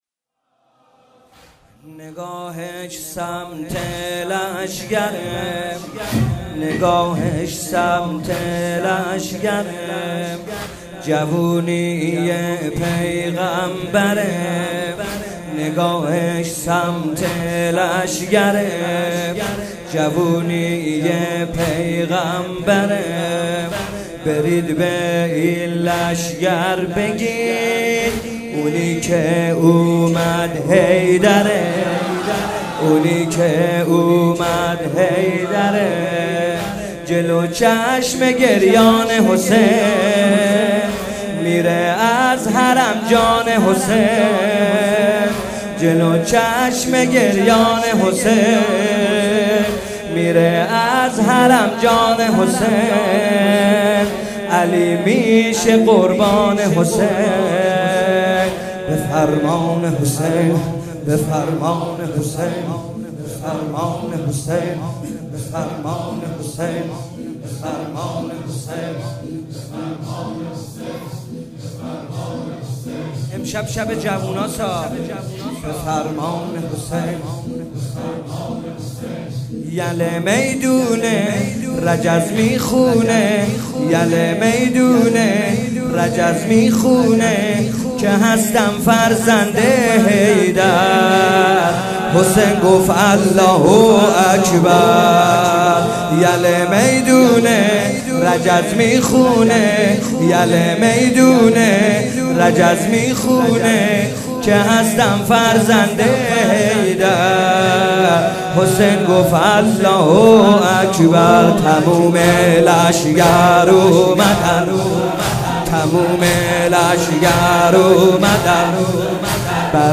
شب هشتم محرم 95 - تک - نگاهش سمت لشکر